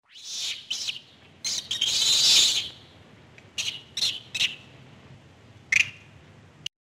Для макак-резусов характерно использование при общении друг с другом нескольких типов криков.
rhesus-monkey.mp3